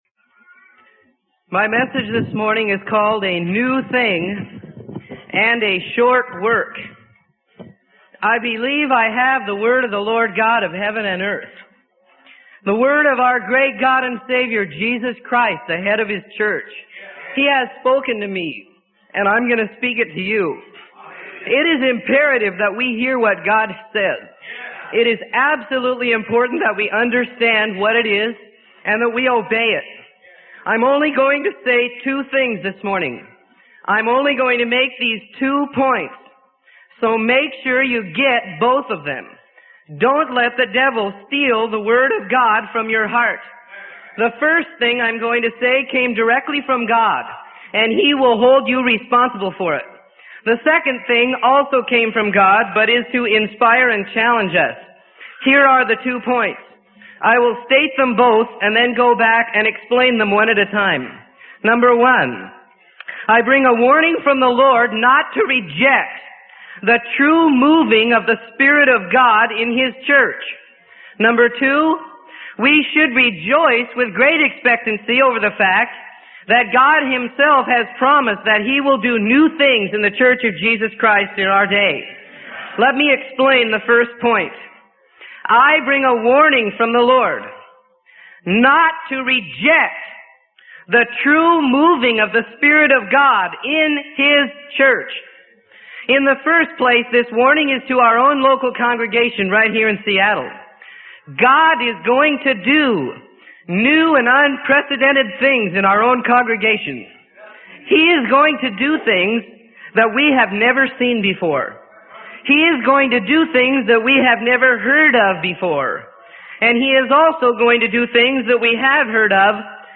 Sermon: A New Thing and a Short Work - Freely Given Online Library